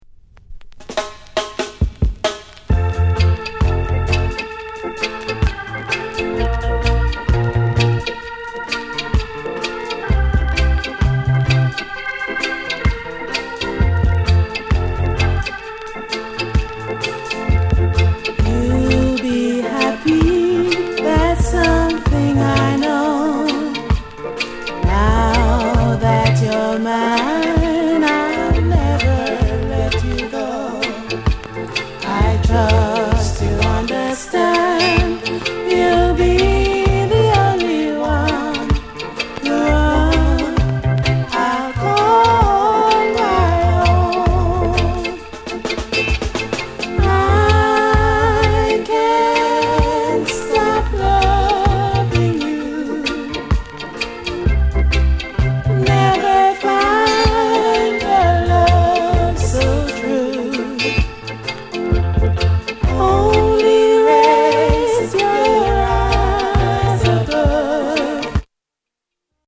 ��VG�� �٤��ʽ����礳���礳����ޤ��� ������ NICE FEMALE LOVERS ROCK